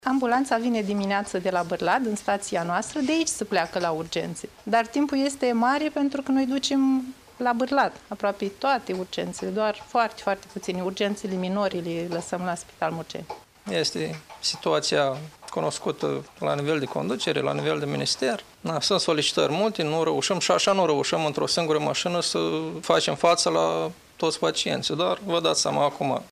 Angajaţii substaţiei de la Murgeni, prezenţi la serviciu, spun că situaţia a scăpat cu totul de sub control: